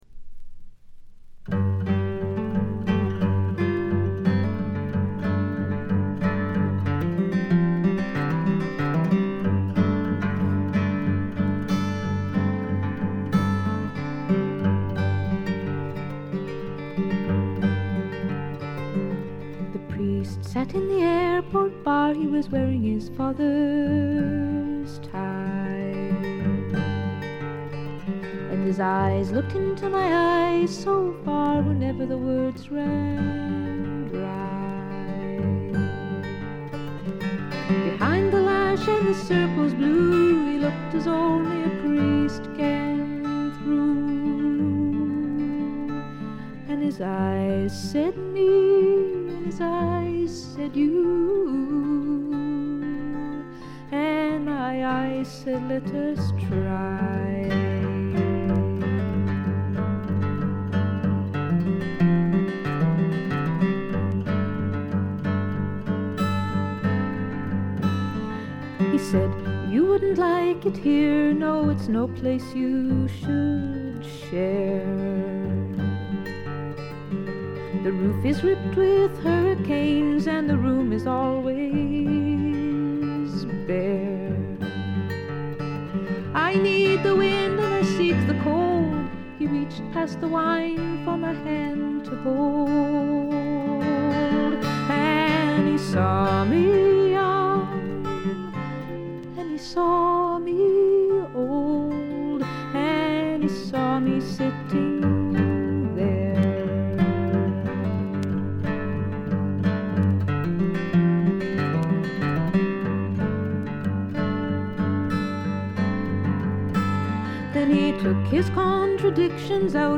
軽微なバックグラウンドノイズ、チリプチ。散発的なプツ音少し。
試聴曲は現品からの取り込み音源です。